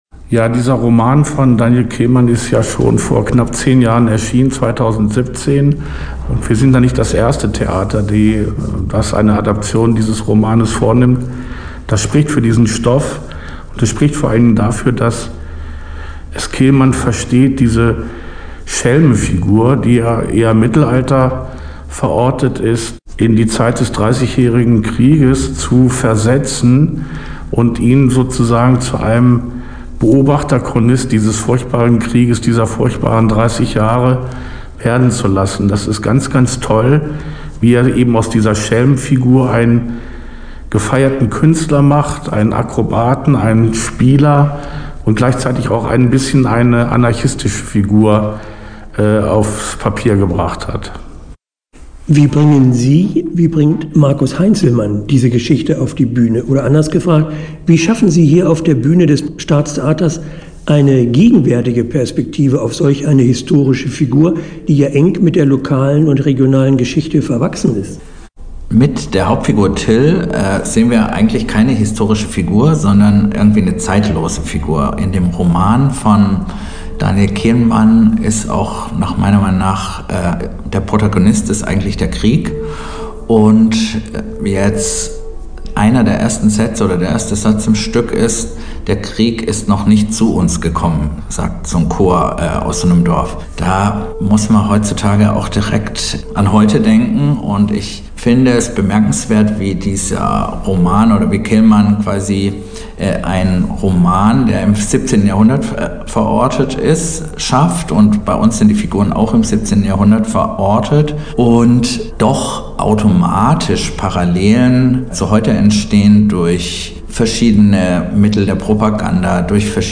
Interview-Tyll.mp3